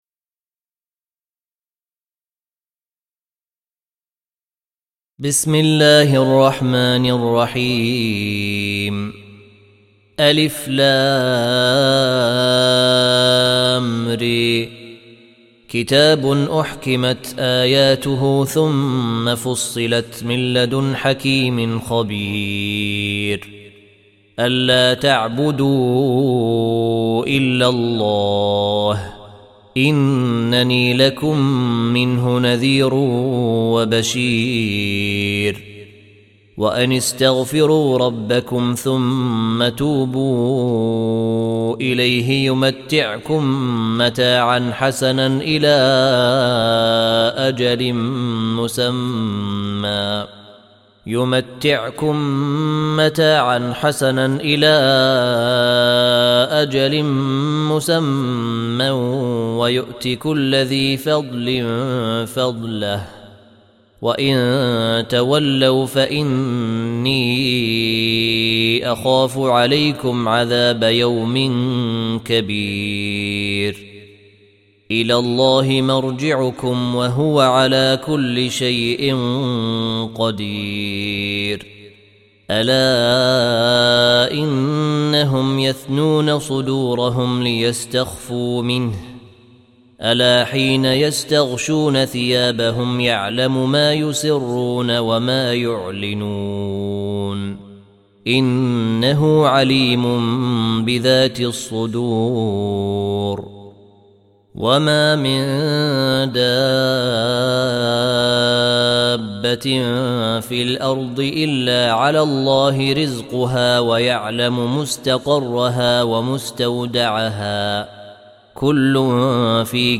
Surah Repeating تكرار السورة Download Surah حمّل السورة Reciting Murattalah Audio for 11. Surah H�d سورة هود N.B *Surah Includes Al-Basmalah Reciters Sequents تتابع التلاوات Reciters Repeats تكرار التلاوات